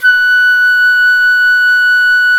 Index of /90_sSampleCDs/Roland LCDP04 Orchestral Winds/CMB_Wind Sects 1/CMB_Wind Sect 3
FLT FL AC 0N.wav